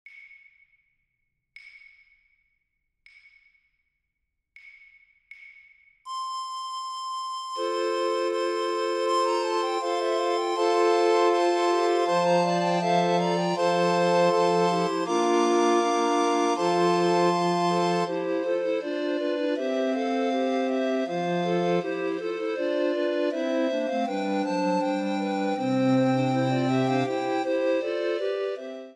The motet sets Revelation 5:5 in jubilant polyphony, celebrating the triumph of the Lion of Judah.
This arrangement for recorder orchestra (ATBGb/ATBGb) preserves the motet’s antiphonal texture and rhythmic vitality.